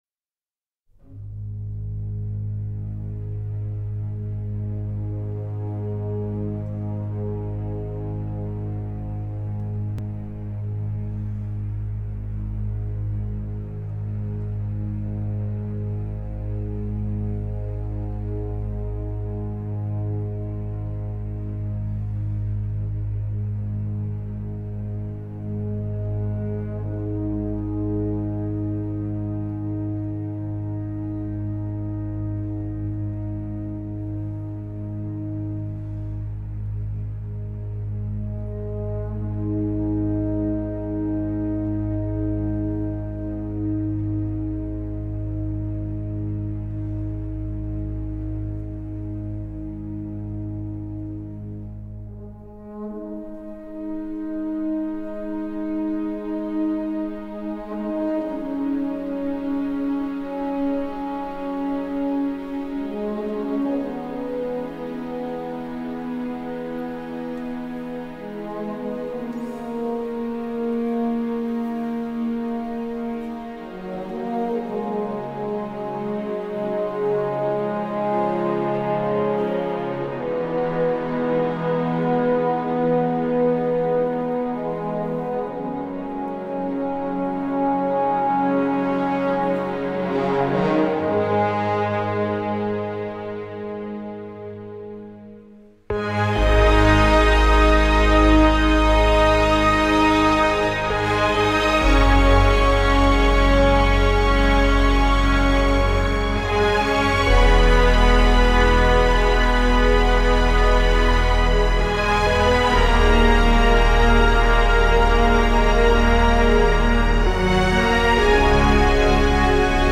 Hire orchestra suite material